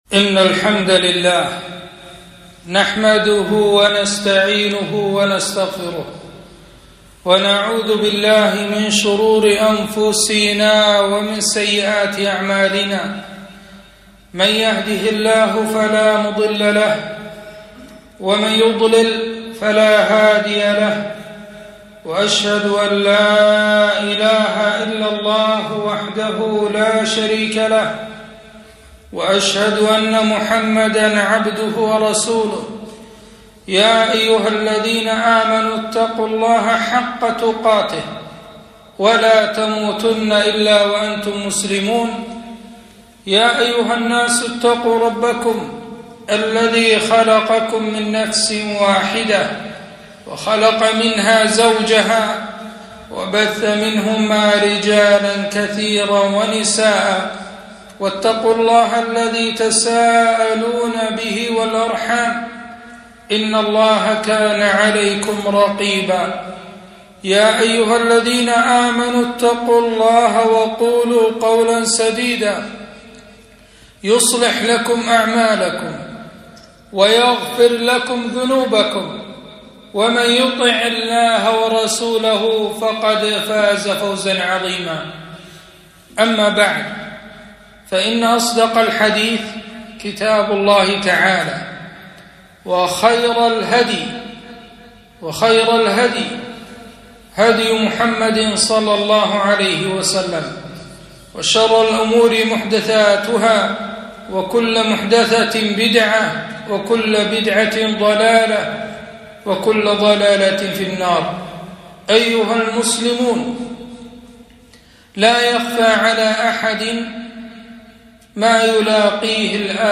خطبة - الإجازة الصيفية وتربية الأبناء